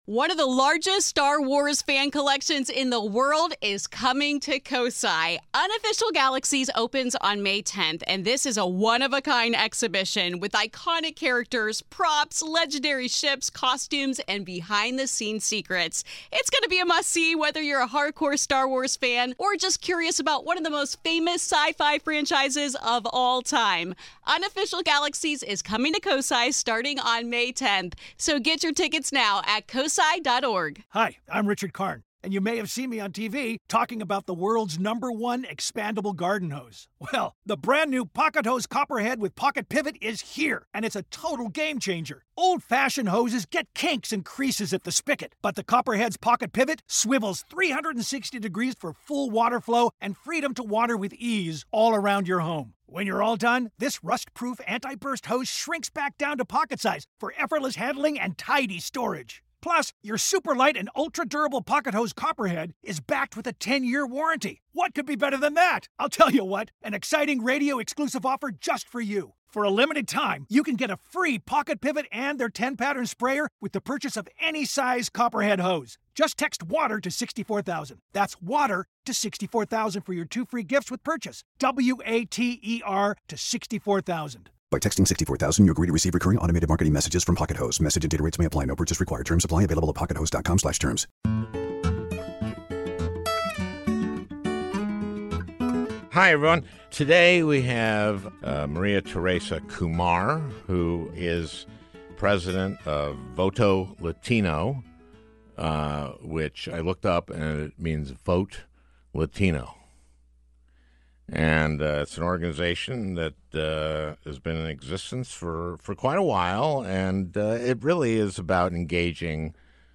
The president of Voto Latino, discusses voter suppression, the Wall, and immigration. At turns funny, then tragic, and angry. A fun and moving interview.